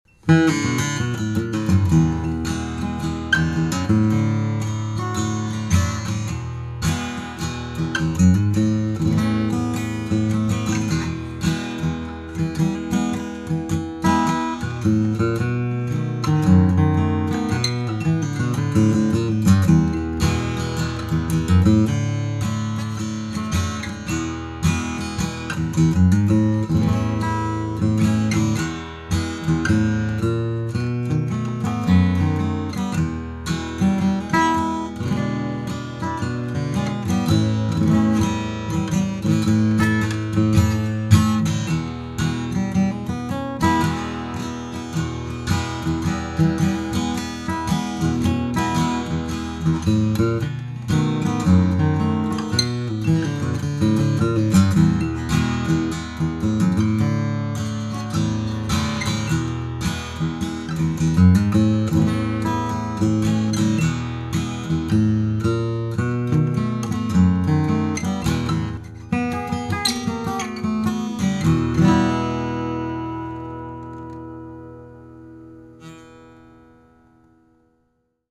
Newest 20 Songs flatpicked guitar songs which Flatpicker Hangout members have uploaded to the website.